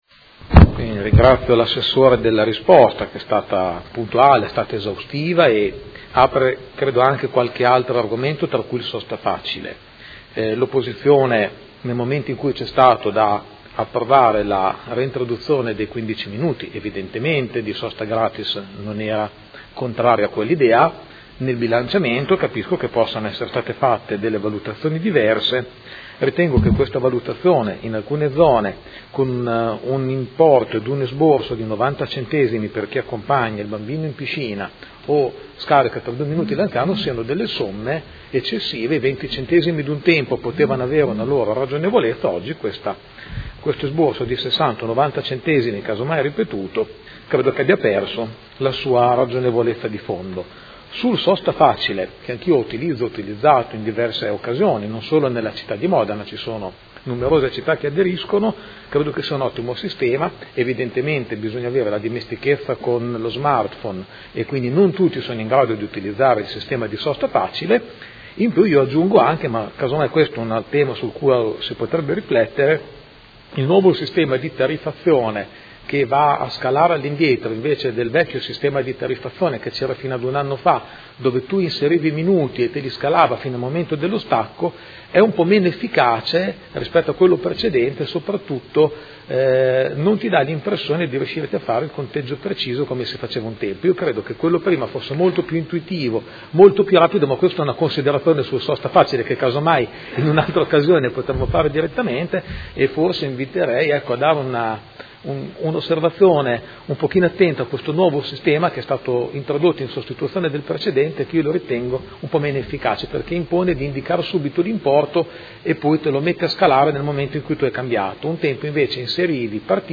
Seduta del 19/01/2017 Interrogazione del Gruppo F.I. avente per oggetto: Importi minimi per la sosta in alcune zone della città. Replica